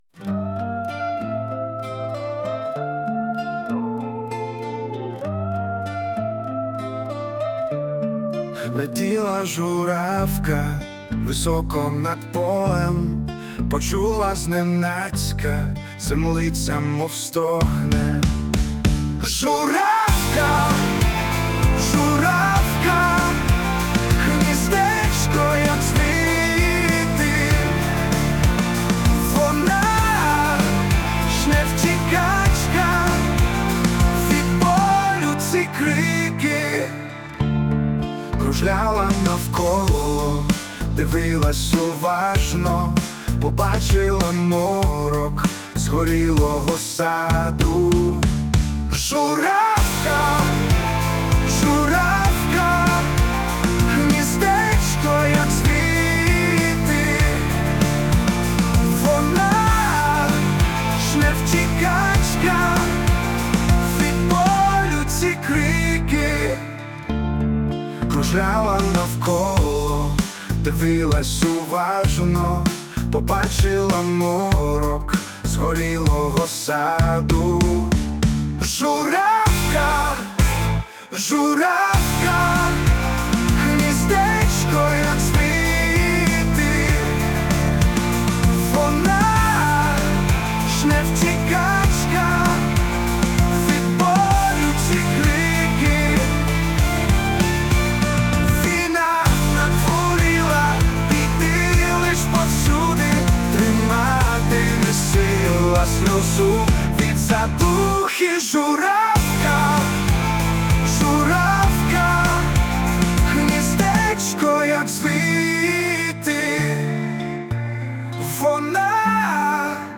Музична композиція створена за допомогою ШІ
як народна пісня... сумна журавка hi
Душевна, зворушна пісня.